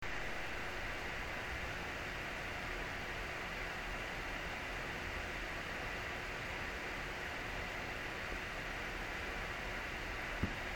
Aušinimo ir triukšmo rezultatai
Pure Rock LP (2500 RPM @37,5 dBA)